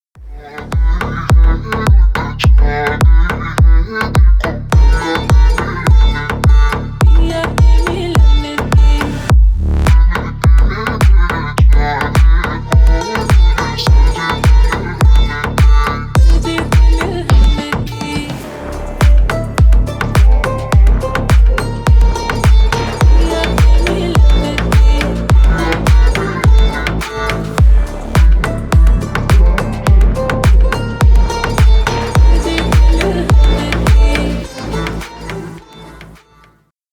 клубные # громкие # восточные